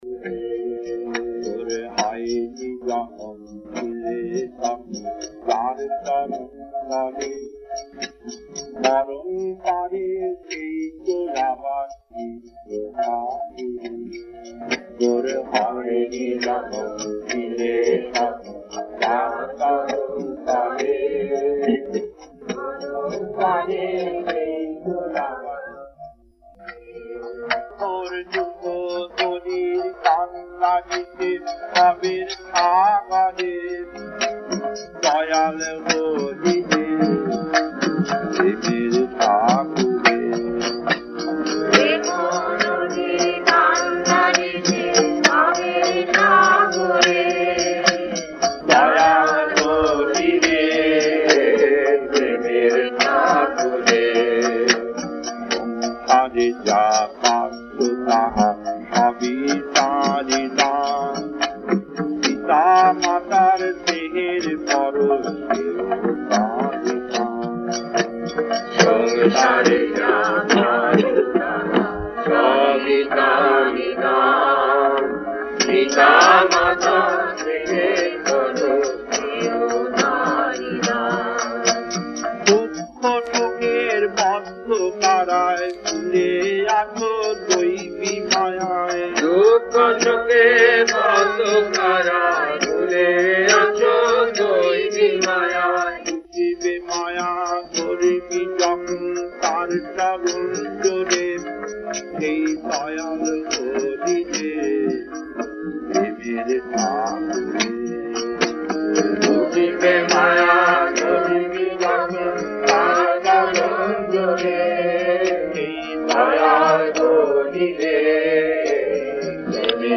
Kirtan G9-2 1: Hoeni Jonom Chiley (Ore aar Kotojon) 2: Ondhokarer Swami (E Ondhokar) 3: Andhakarer Utso Hote 4: Bela Je Bohe Jae 5: Shyamal Shyamal 6: Chiro Janam Janam Das 7: Raghu Kulapati Ramchandra